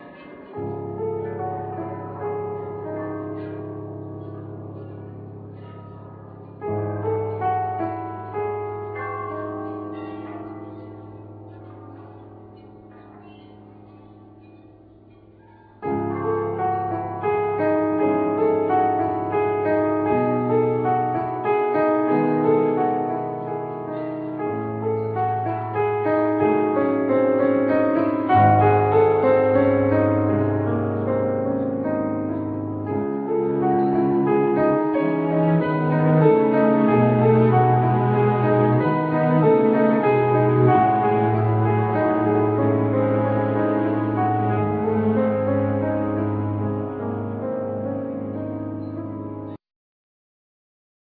Piano,Vibes,Linen sheet
Cello
Violin